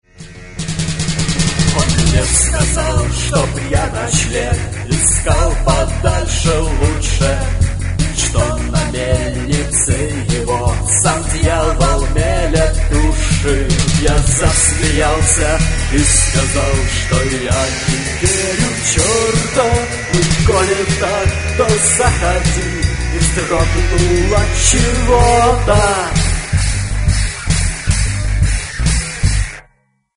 Гитары, бас, вокал
Барабаны
фрагмент (107 k) - mono, 48 kbps, 44 kHz